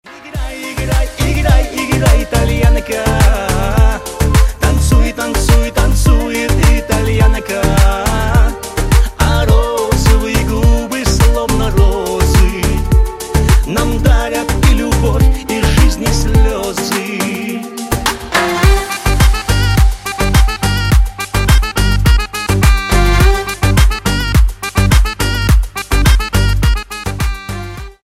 Кавказские Рингтоны
Поп Рингтоны